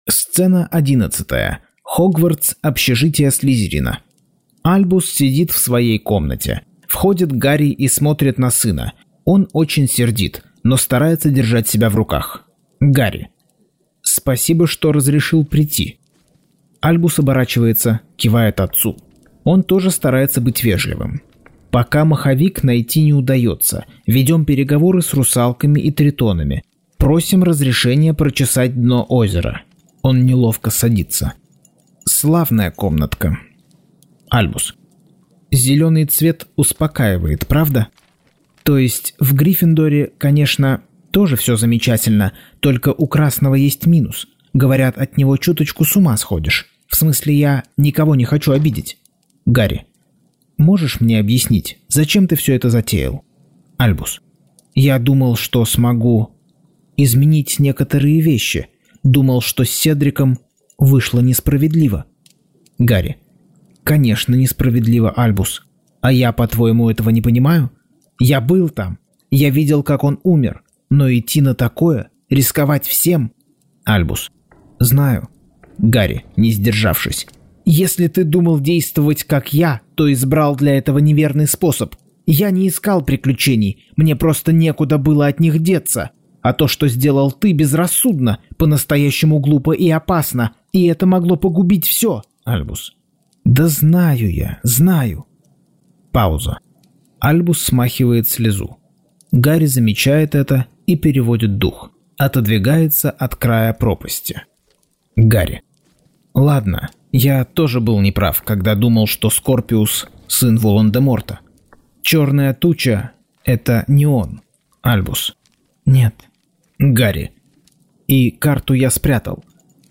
Аудиокнига Гарри Поттер и проклятое дитя. Часть 43.